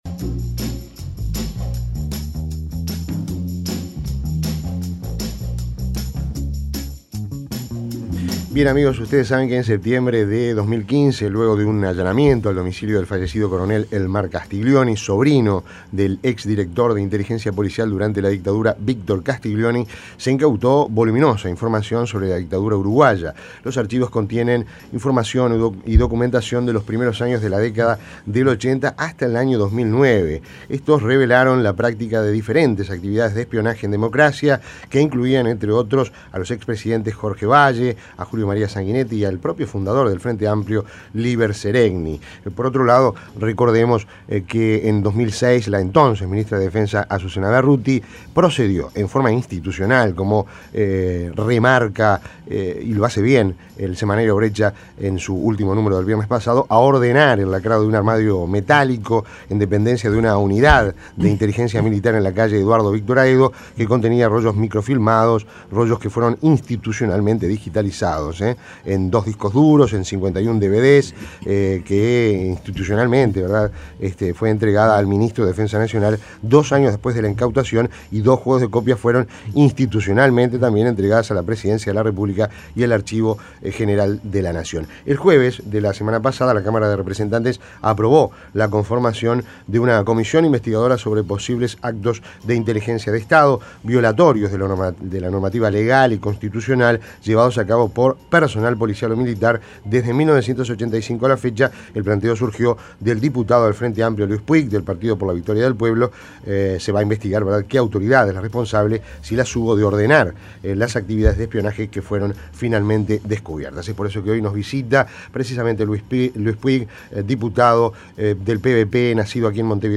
Entrevista en Rompkbzas